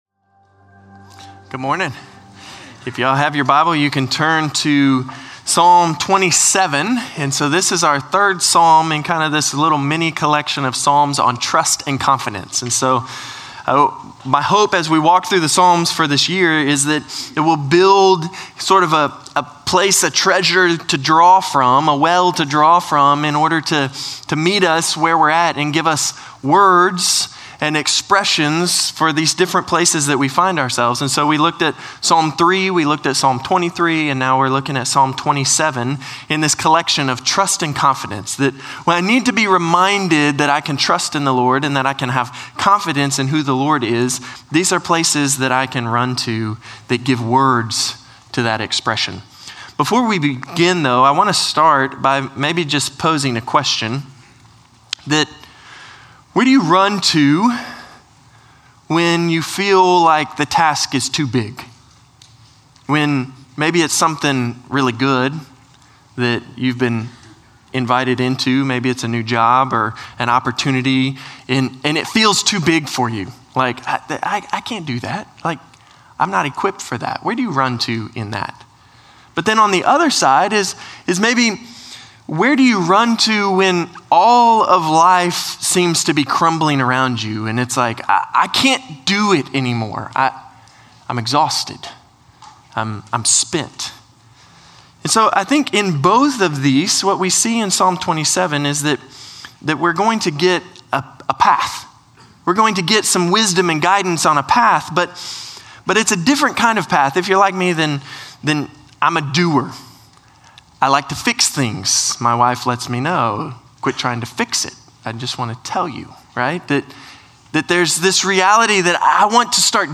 Norris Ferry Sermons Sept. 29, 2024 -- Psalms -- Psalm 27 Sep 29 2024 | 00:33:53 1x 00:00 / 00:33:53 Subscribe Share Spotify RSS Feed Share Link Embed